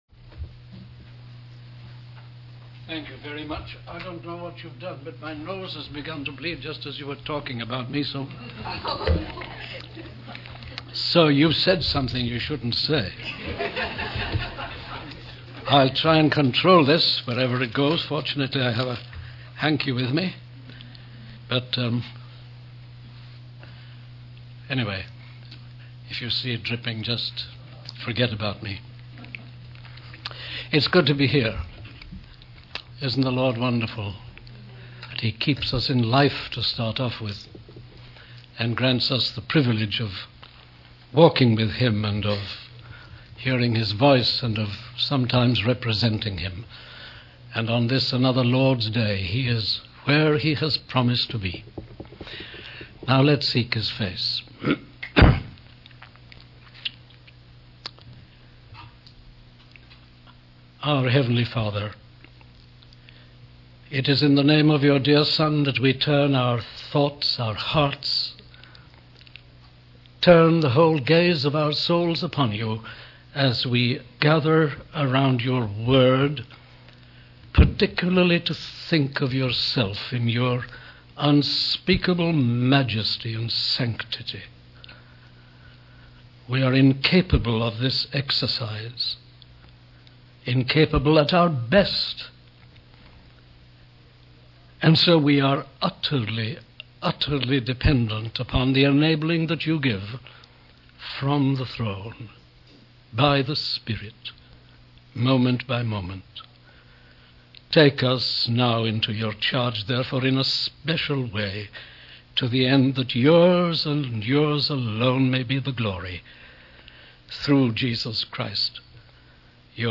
In this sermon, the speaker discusses the topic of the holiness of God. He emphasizes that holiness is not just a characteristic of God, but it also represents His will for His creatures.